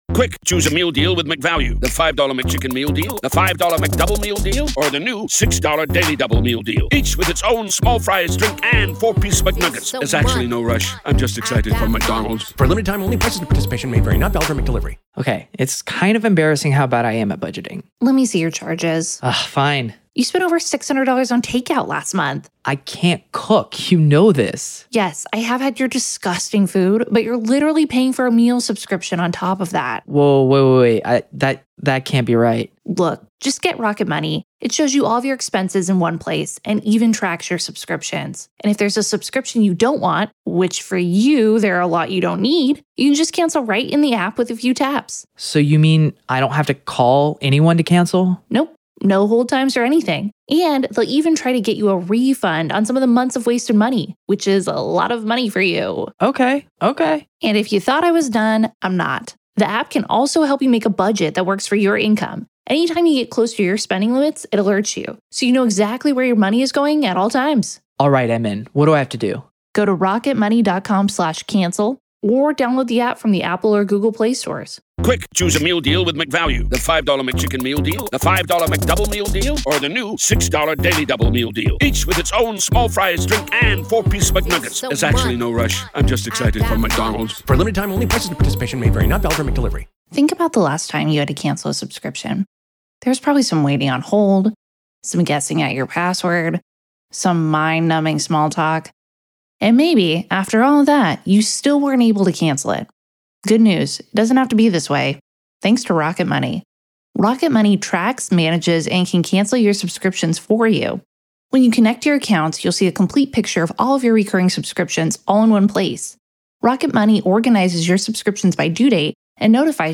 LIVE COURTROOM COVERAGE — NO COMMENTARY